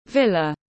Villa /ˈvɪlə/